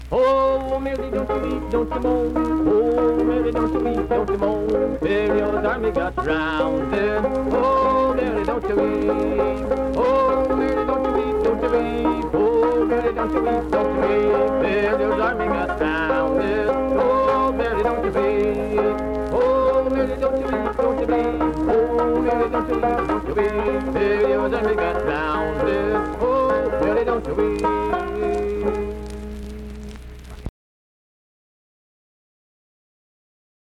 Vocal performance accompanied by banjo.
Hymns and Spiritual Music
Voice (sung), Banjo
Wood County (W. Va.), Vienna (W. Va.)